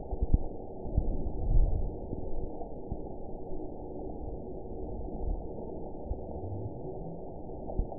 event 922631 date 02/10/25 time 03:27:50 GMT (2 months, 2 weeks ago) score 8.25 location TSS-AB03 detected by nrw target species NRW annotations +NRW Spectrogram: Frequency (kHz) vs. Time (s) audio not available .wav